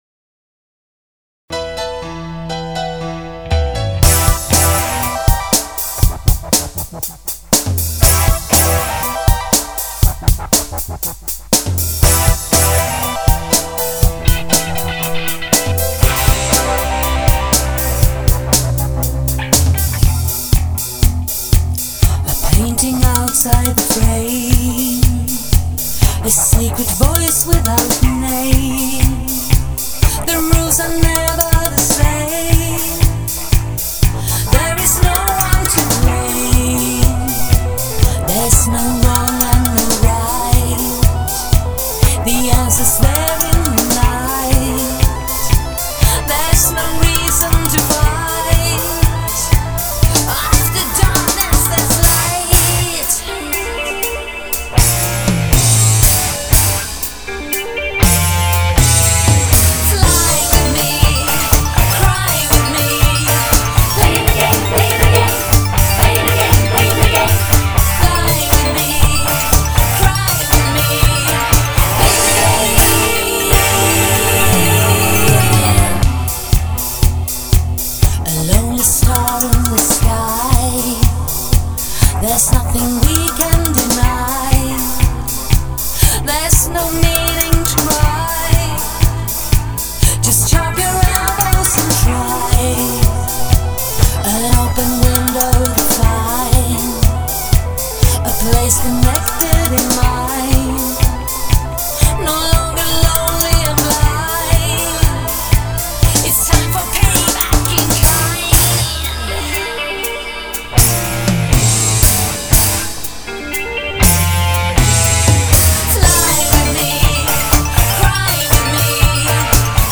In this song I tried to recreate the sounds I used to use.